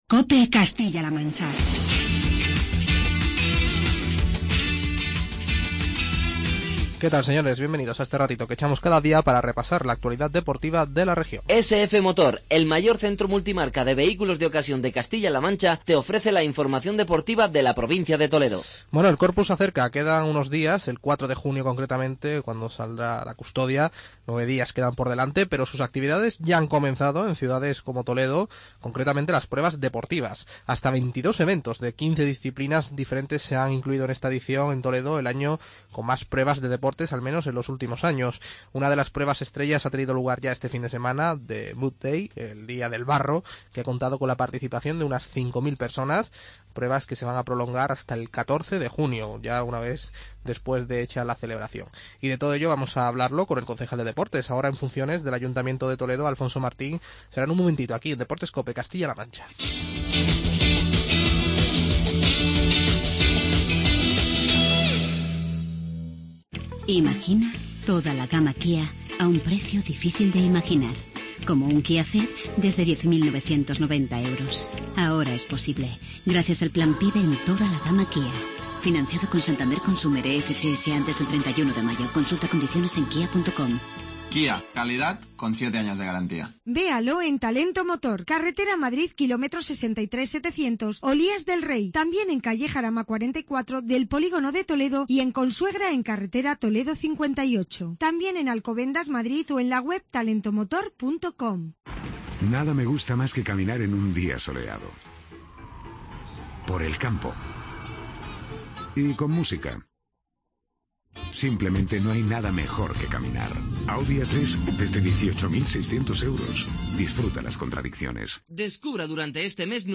Charlamos con el concejal de Deportes del Ayuntamiento de Toledo, Alfonso Martín